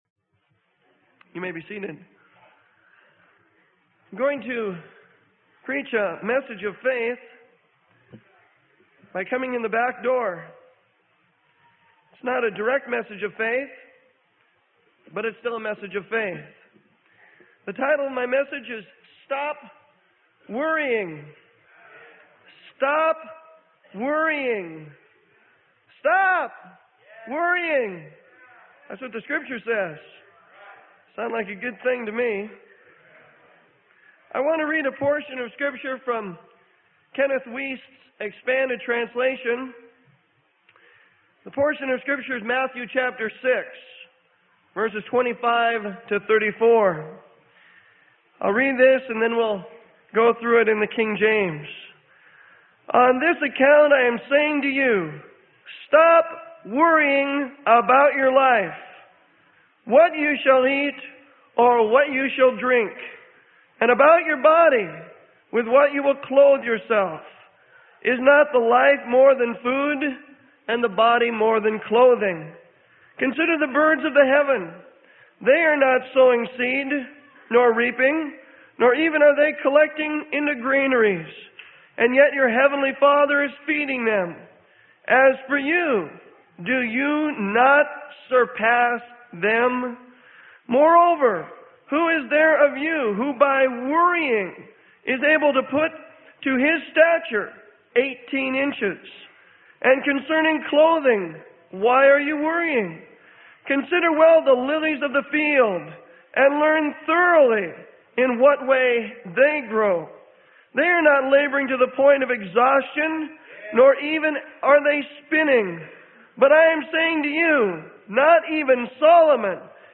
Sermon: Stop Worrying - Freely Given Online Library